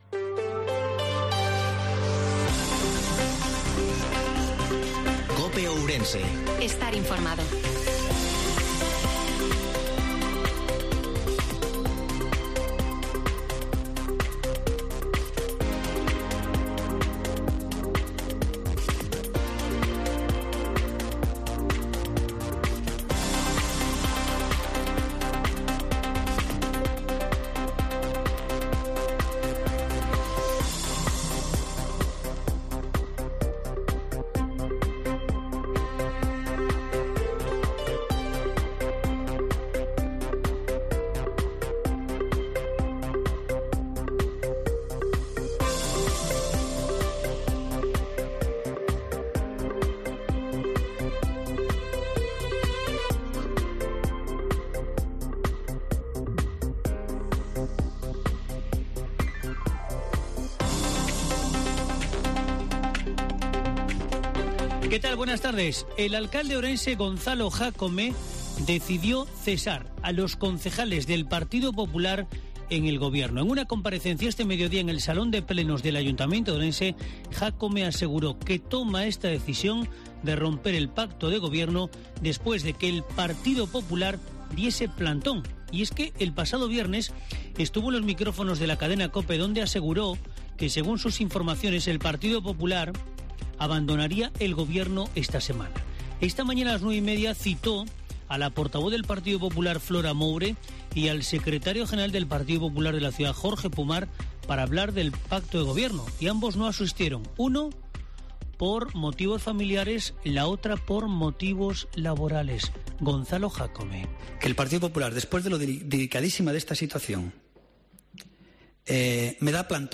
INFORMATIVO MEDIODIA COPE OURENSE-26/07/2022